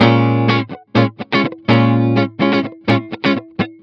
05 GuitarFunky Loop B.wav